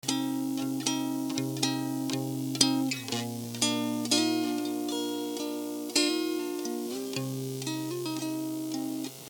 High frequencies passed, low frequencies attenuated
High Pass